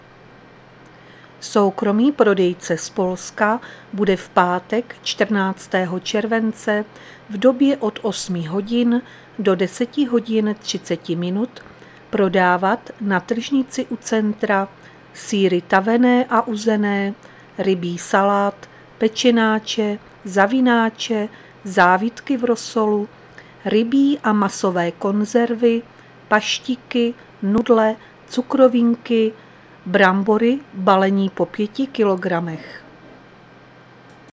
Záznam hlášení místního rozhlasu 13.7.2023